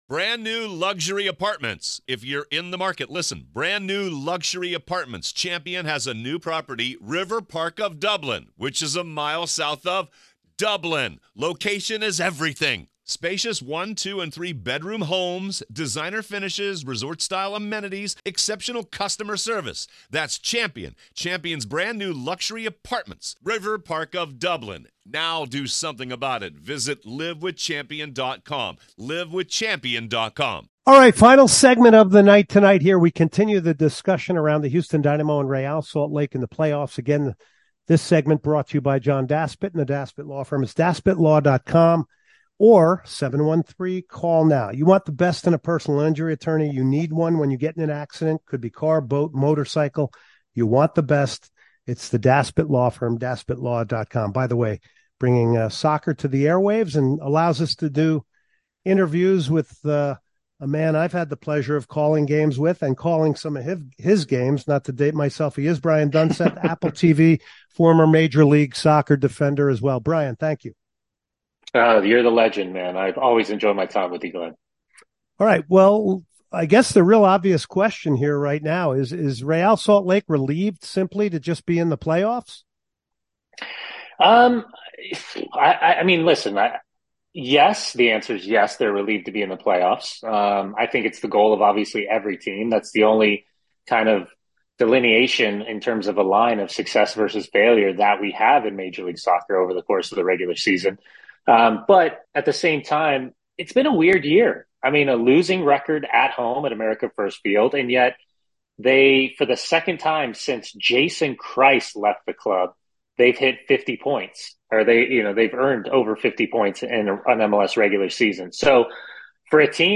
He joins the podcast for an interview, talking all things MLS, playoffs, behind the scenes, & much more.